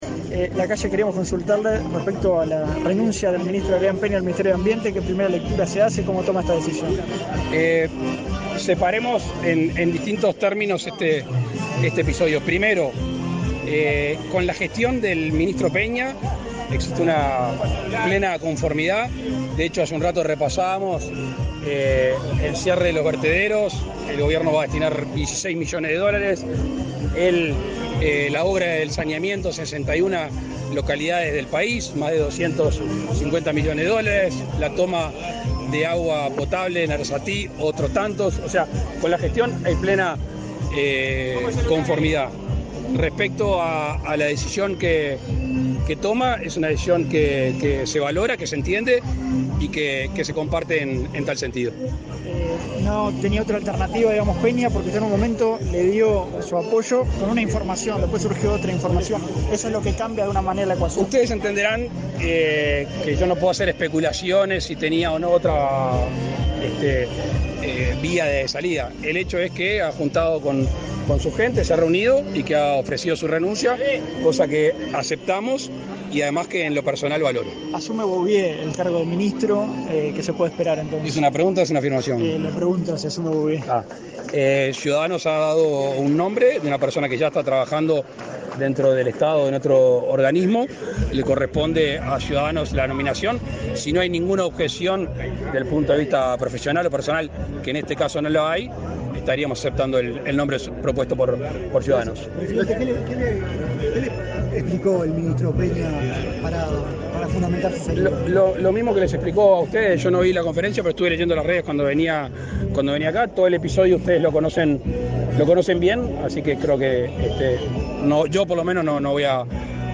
Declaraciones a la prensa del presidente de la República, Luis Lacalle Pou, en San Gregorio de Polanco
Declaraciones a la prensa del presidente de la República, Luis Lacalle Pou, en San Gregorio de Polanco 31/01/2023 Compartir Facebook X Copiar enlace WhatsApp LinkedIn Tras participar en la inauguración de calles e inicio de obras de saneamiento en San Gregorio de Polanco, en el departamento de Tacuarembó, este 30 de enero, el presidente de la República, Luis Lacalle Pou, realizó declaraciones a la prensa.